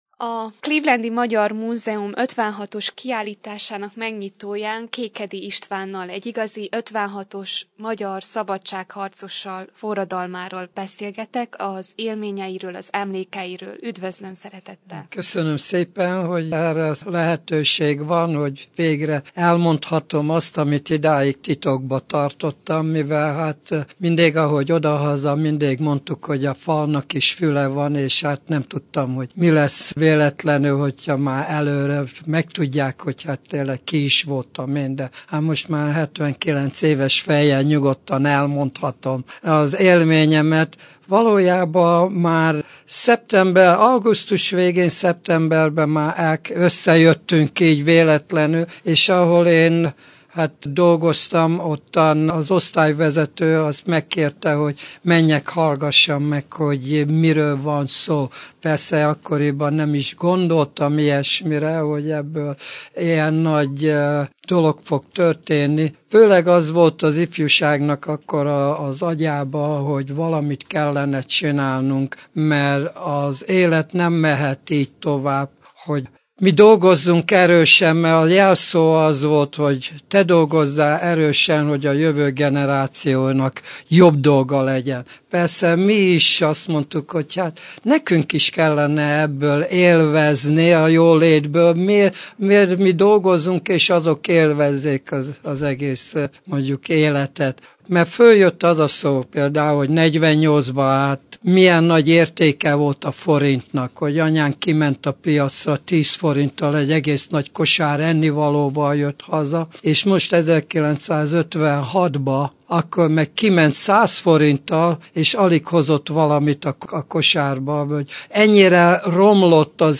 akivel később egy interjú is készült a rádió számára.